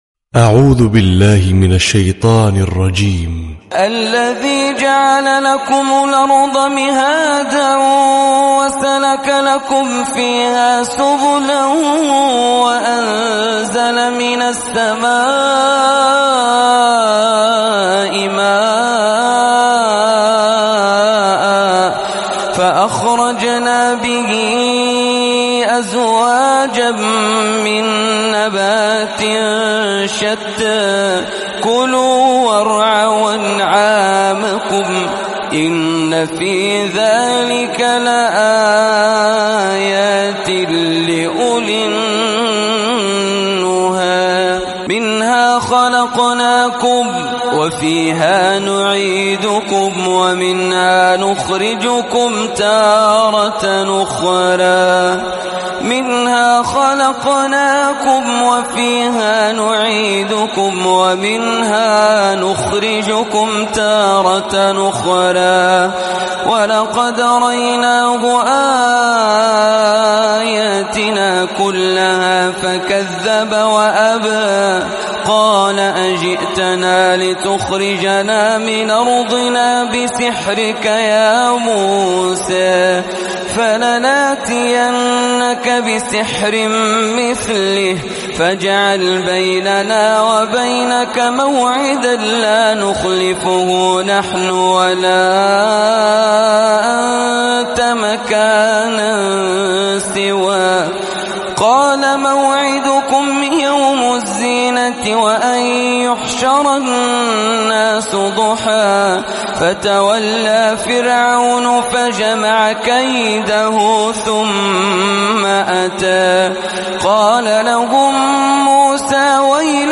🕋🌻•تلاوة صباحية•🌻🕋
★برواية ورش عن نافع★